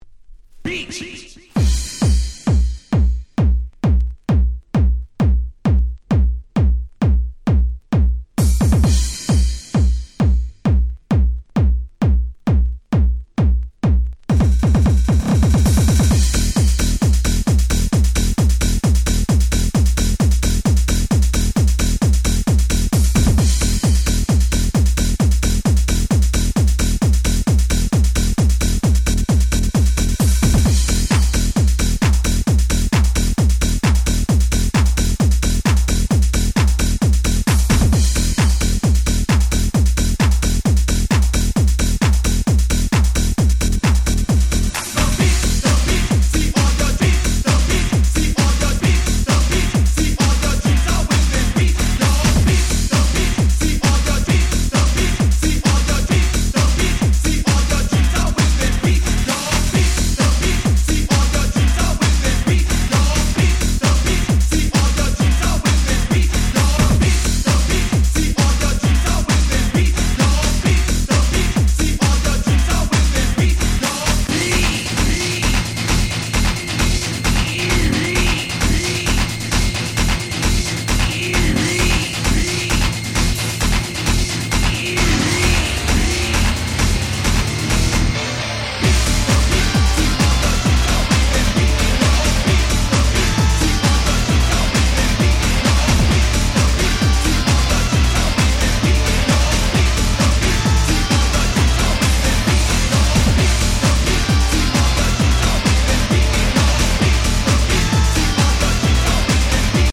86' Old School Hip Hop Classic !!